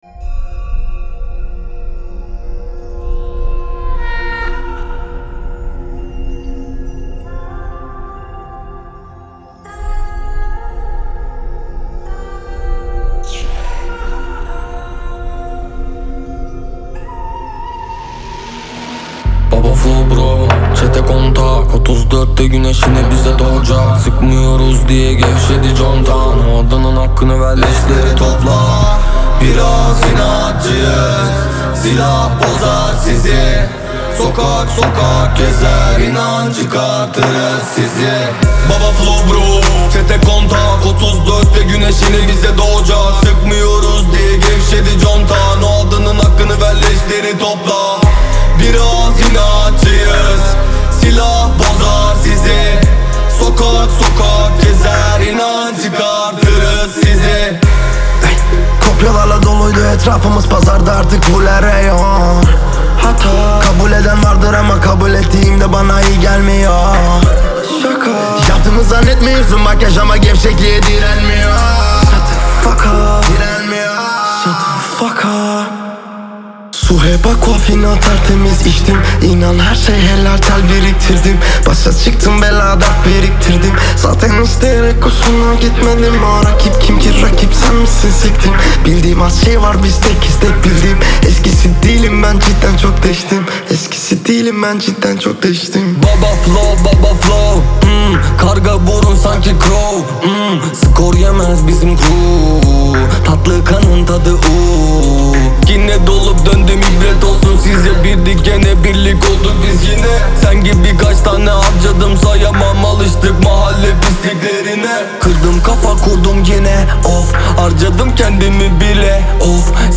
Трек размещён в разделе Рэп и хип-хоп / Турецкая музыка.